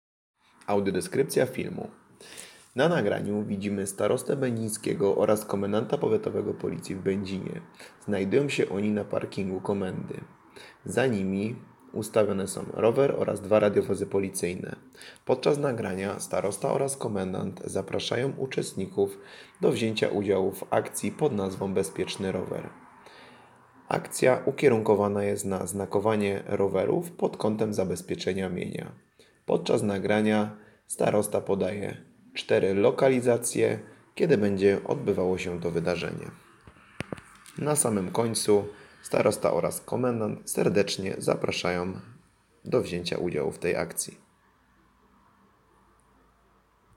Nagranie audio Audiodeskrypcja_filmu_Akcja_Bezpieczny_Rower.mp3